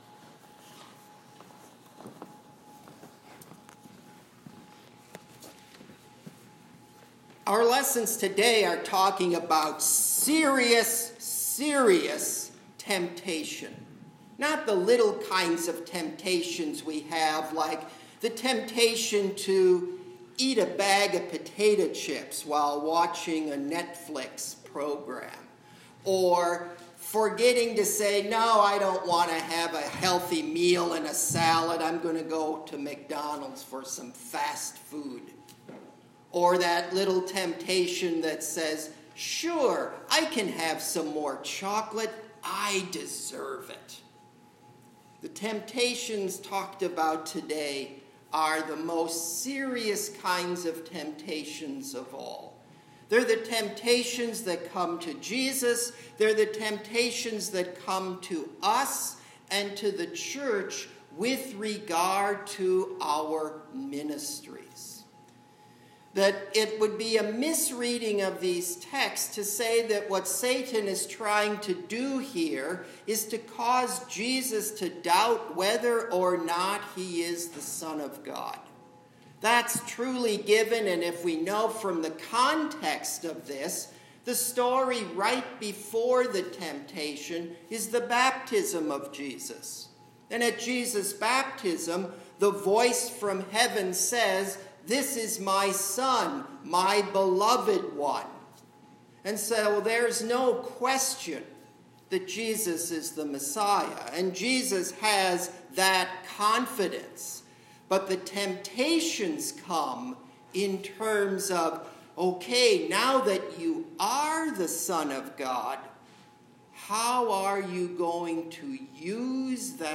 A Sermon
FIRST SUNDAY IN LENT, March 1, 2020